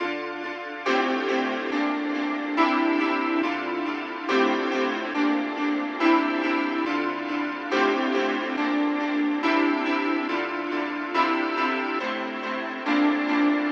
描述：陷阱钢琴140bpm
Tag: 140 bpm Trap Loops Piano Loops 2.31 MB wav Key : Unknown